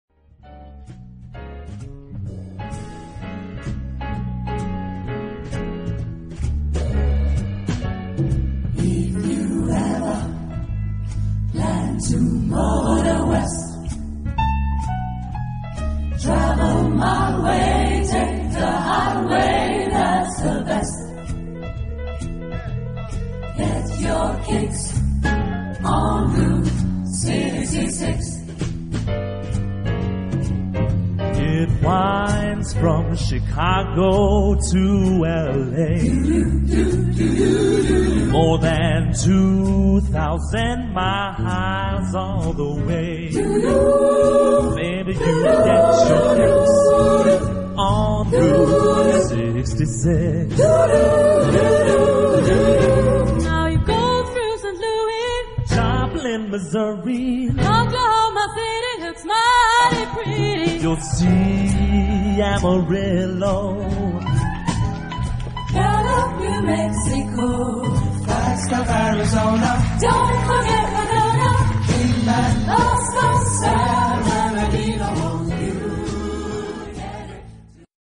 SSATB (5 voces Coro mixto) ; Partitura de coro.
Coral jazz.